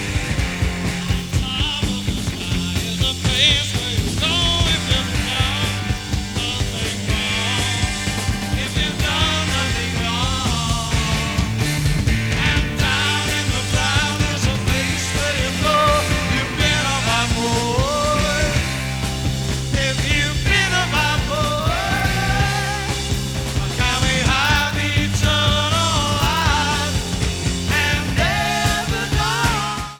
Format/Rating/Source: CD - A - Soundboard